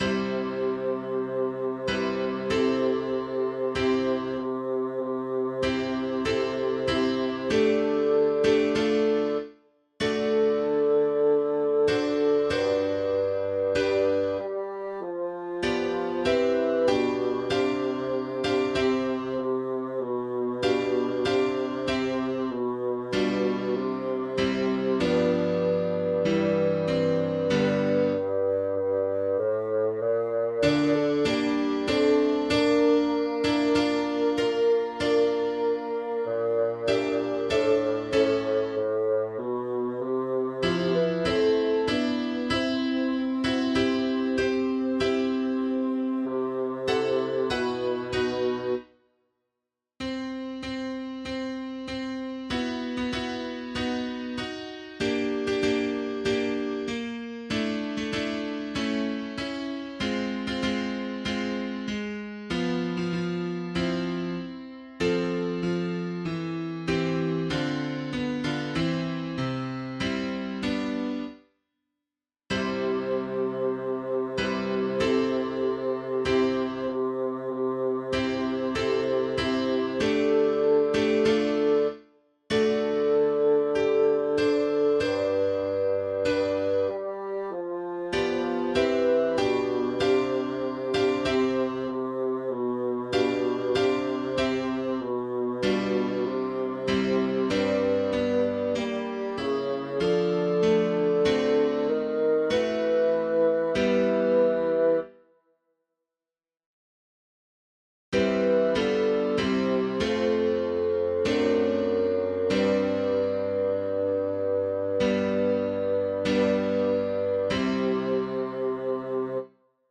locus iste-bas.mp3